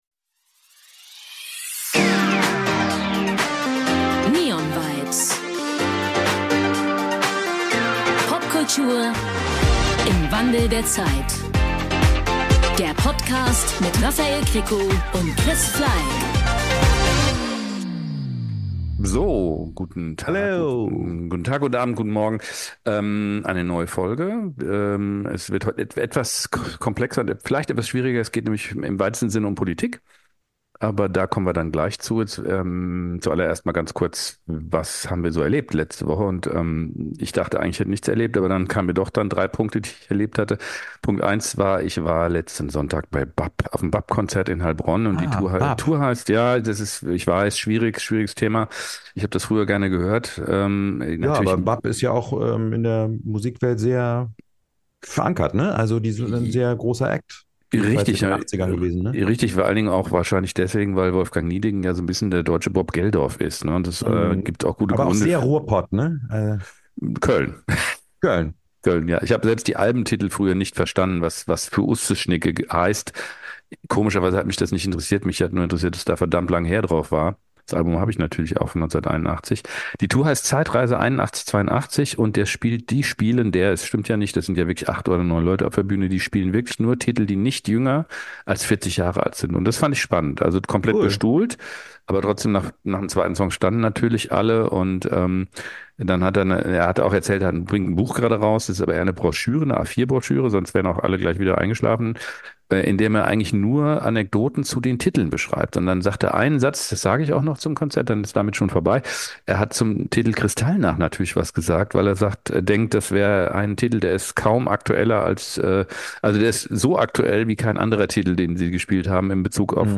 Beschreibung vor 1 Jahr Inwieweit hat Popkultur nicht nur Einfluss auf gesellschaftliche Themen, sondern auch auf Wahlen, Politik und ihre Protagonisten? Welche Politiker waren und sind glaubwürdige Ikonen und welche haben eher den Charakter von Entertainern, bei denen Inhalte in den Hintergrund treten? Wie hat sich die Art der Propaganda verändert und welche Auswirkungen hat das? Zwei Laien tauschen sich aus.